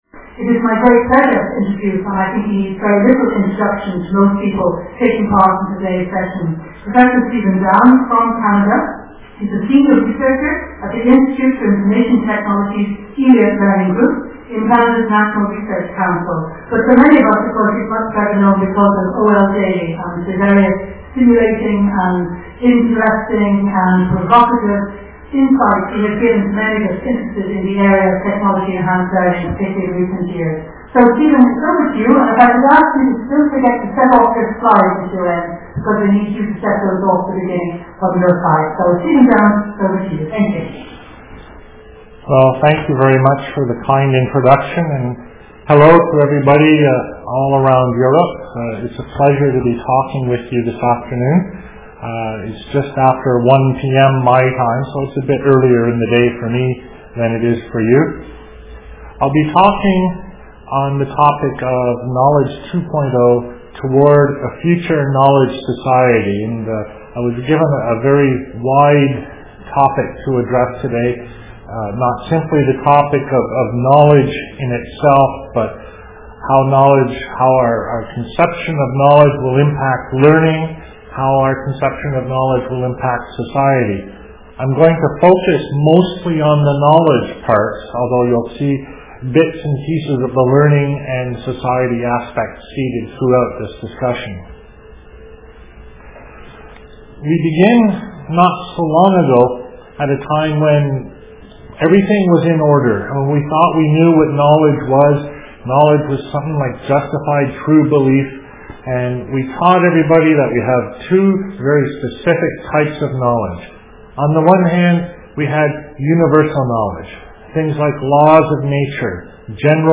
My presentation to the VENUS seminar on Wednesday. In this talk I describe connective knowledge, and in particular I compare it with traditonal knowledge, both on the level of generalizations (which correspond to pattern recognition) and concrete particulars (which correspond to complex linkages between entities). The online seminar was seen in six European locations as well as streamed online.